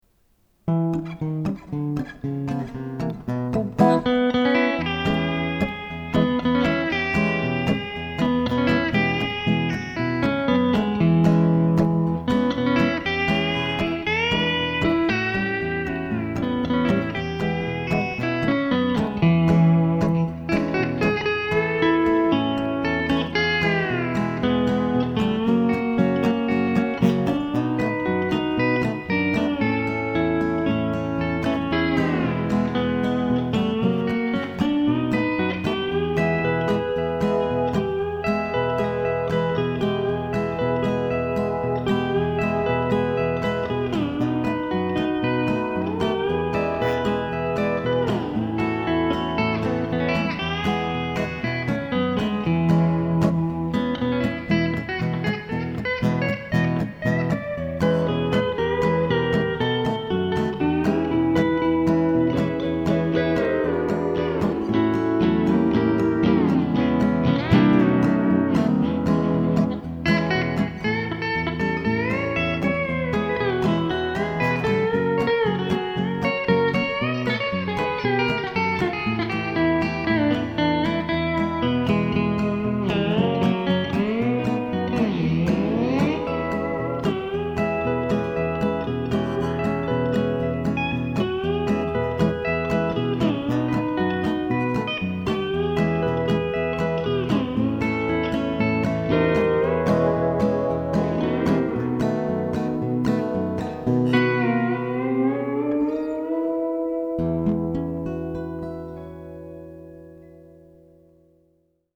I wanted to get close to the E9th timbre on country songs.